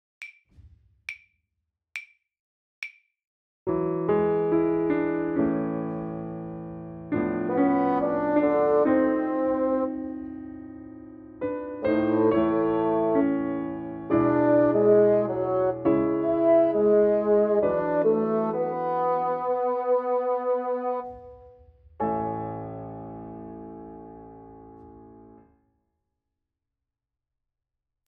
Claviers
91 - Page 42 - déchiffrage 1 - piano seul